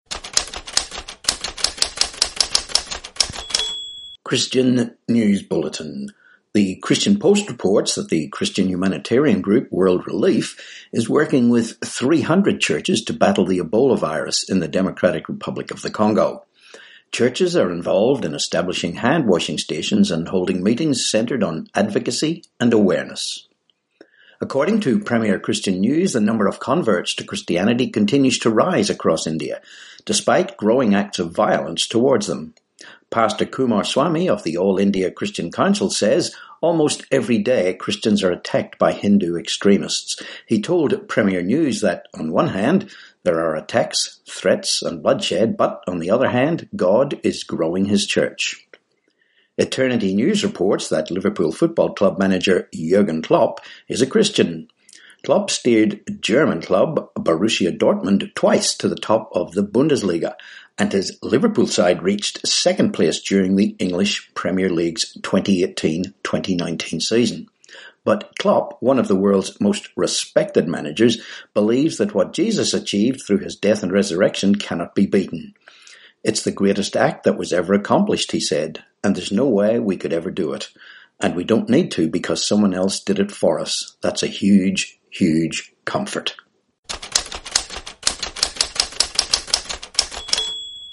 25Aug19 Christian News Bulletin